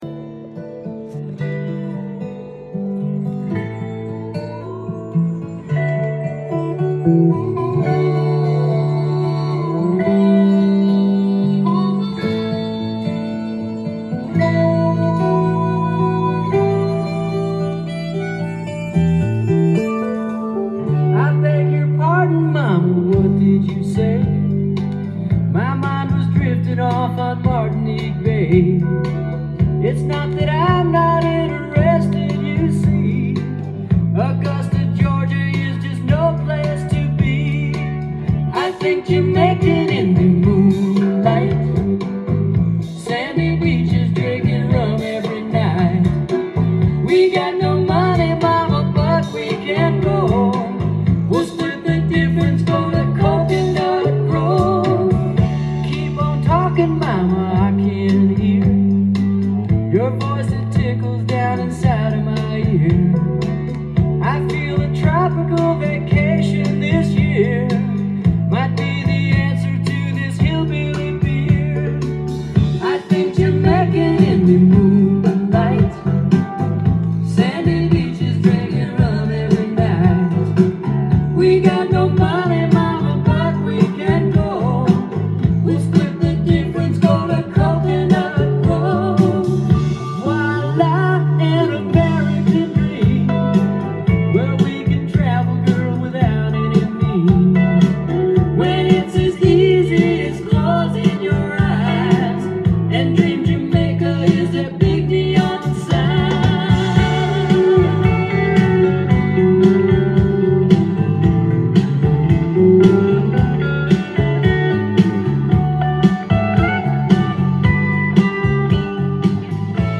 店頭で録音した音源の為、多少の外部音や音質の悪さはございますが、サンプルとしてご視聴ください。
カントリーの要素が強めながらポップな内容に！